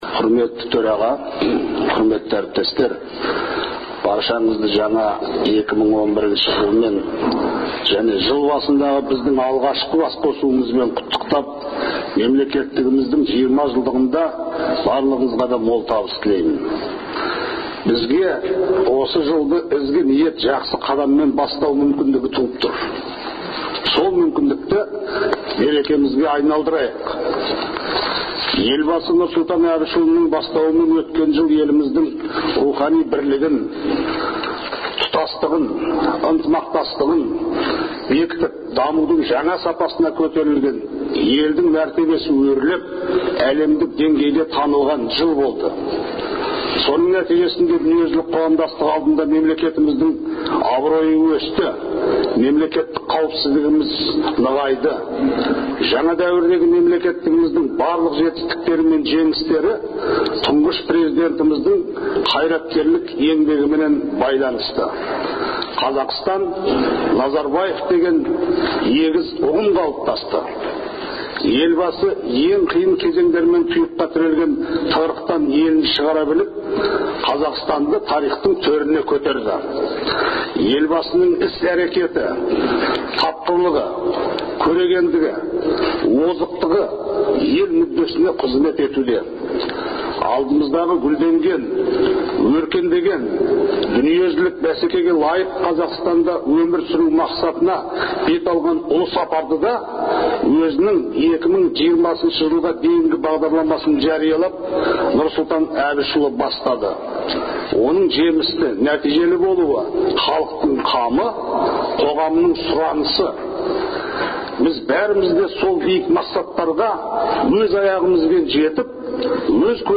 Қазақстан президенті Нұрсұлтан Назарбаевтың өкілетін 2020-шы жылға дейін ұзартуға қатысты Конституцияға өзгеріс енгізу туралы Мәжілістің ұсынысын қолдаған Сенаттың жалпы отырысында сөйлеген Қуаныш Сұлтановтың сөзі.